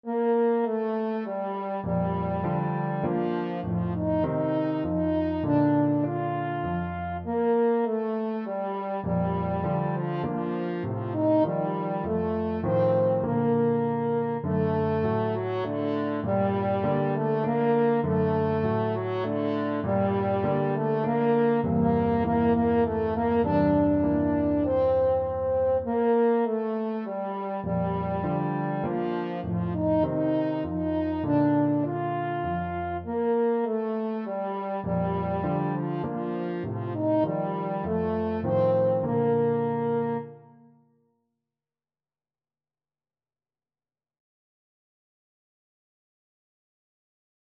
Arrangement for French Horn and Piano
3/4 (View more 3/4 Music)
Allegro moderato (View more music marked Allegro)
Classical (View more Classical French Horn Music)